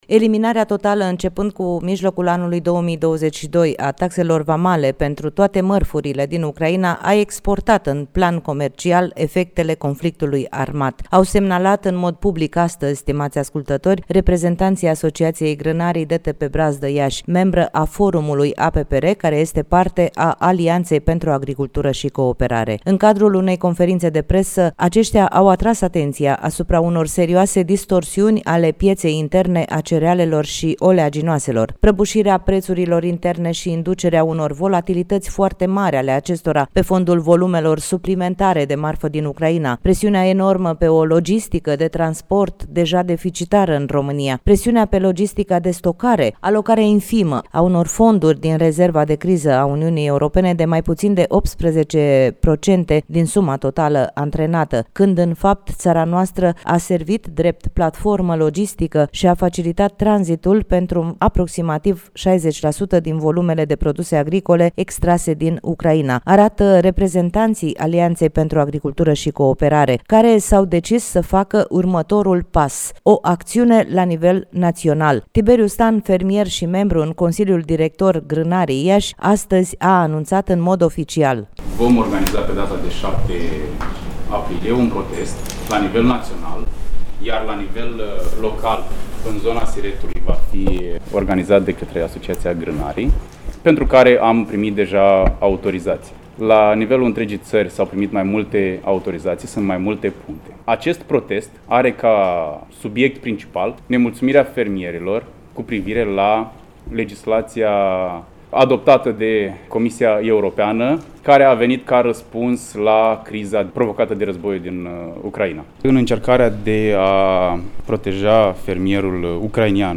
Reportaj de la Conferința de presă